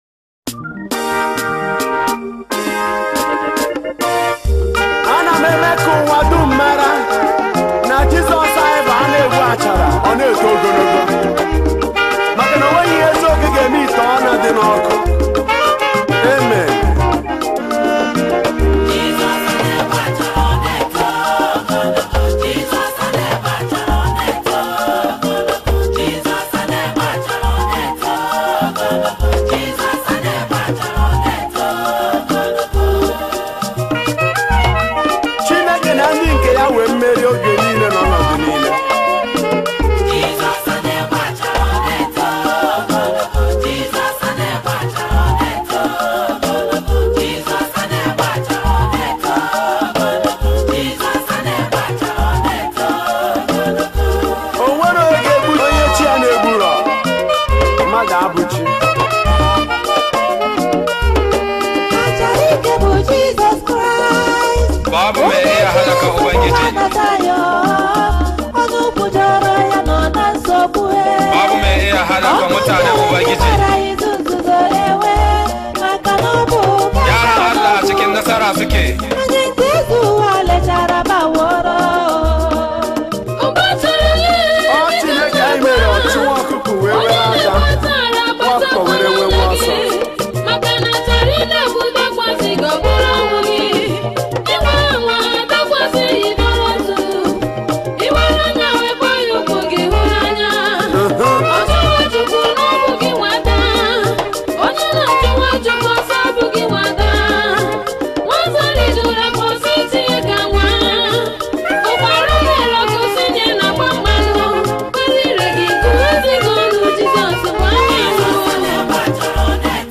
February 8, 2025 Publisher 01 Gospel 0